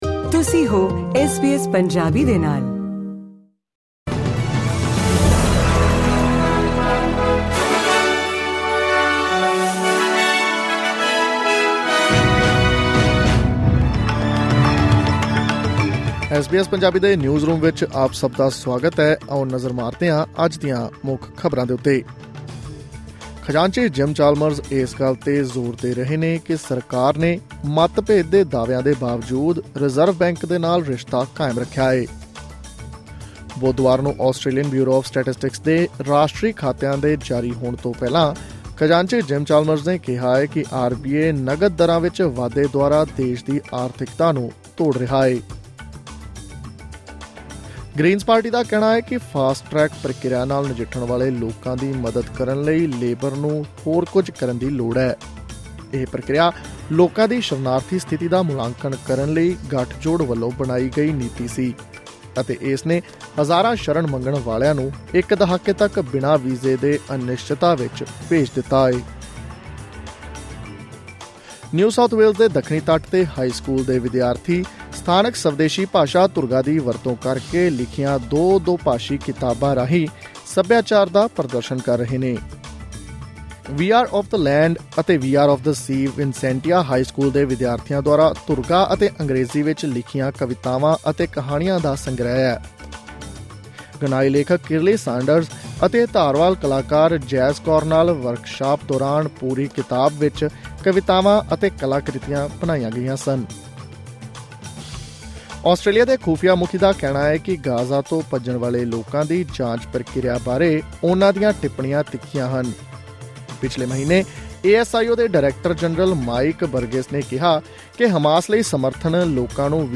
ਐਸ ਬੀ ਐਸ ਪੰਜਾਬੀ ਤੋਂ ਆਸਟ੍ਰੇਲੀਆ ਦੀਆਂ ਮੁੱਖ ਖ਼ਬਰਾਂ: 3 ਸਤੰਬਰ 2024